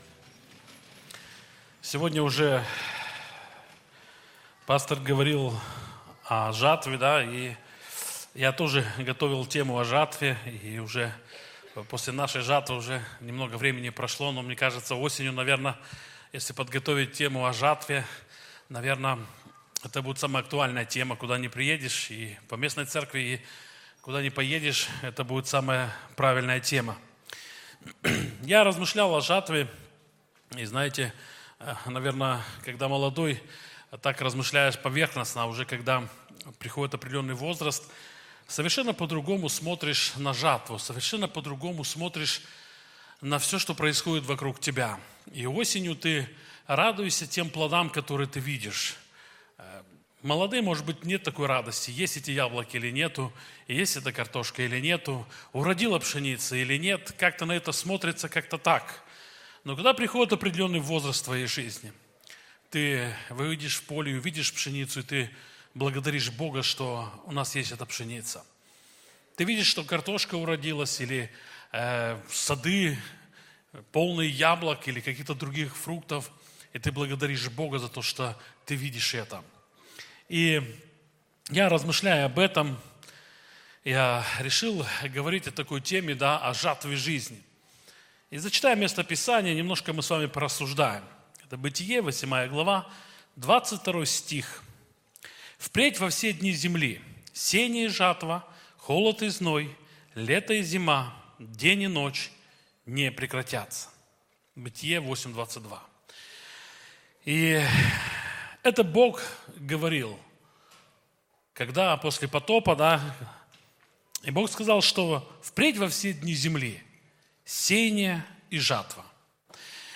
Church4u - Проповеди